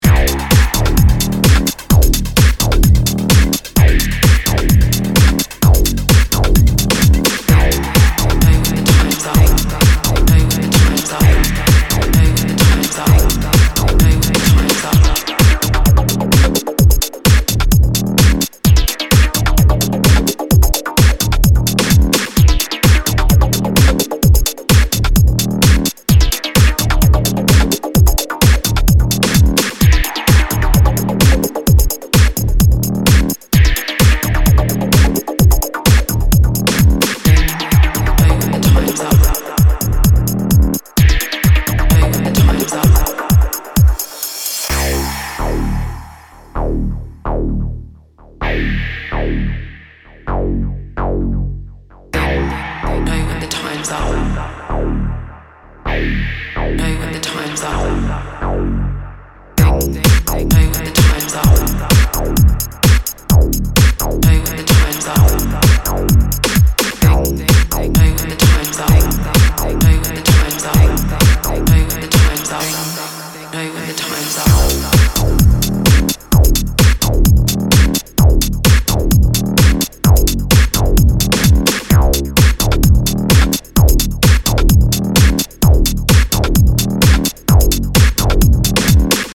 distinctive and hypnotic moods